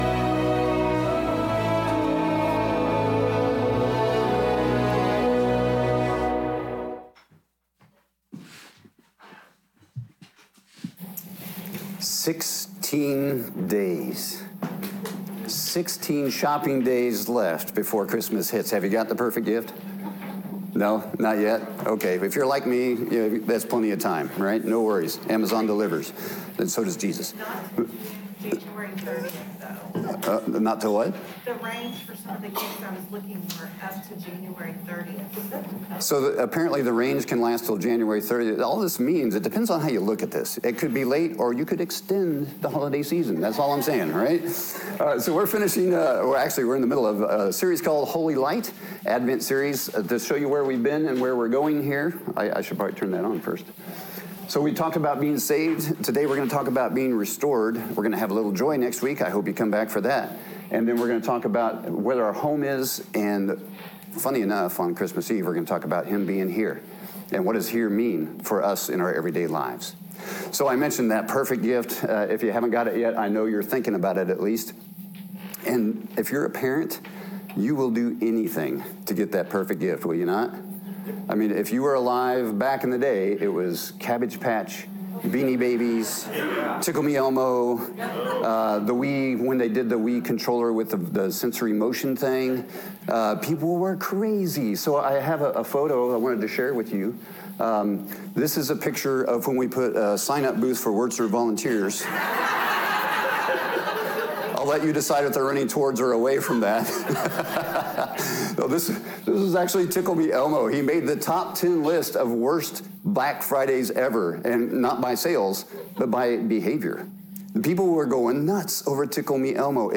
audio-sermon-restored.m4a